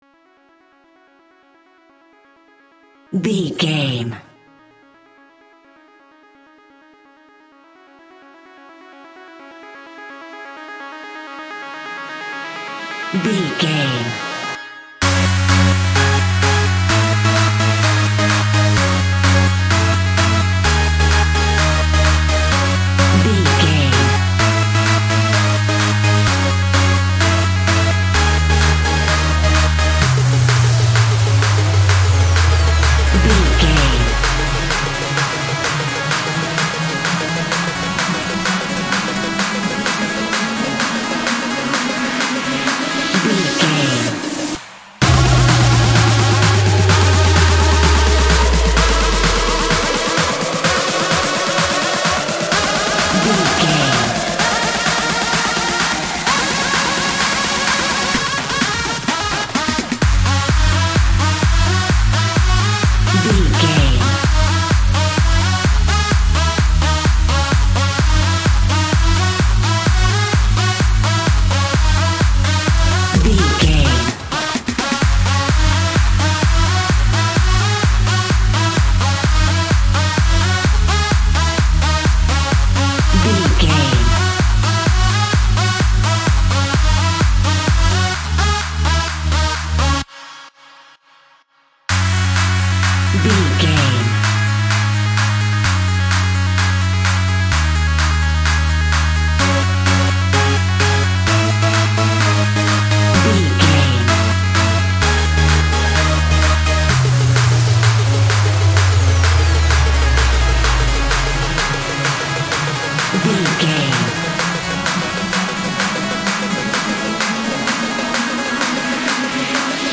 royalty free music
Epic / Action
Fast paced
Aeolian/Minor
C#
dark
futuristic
groovy
synthesiser
drums
drum machine
acid house
electronic
uptempo
instrumentals
synth leads
synth bass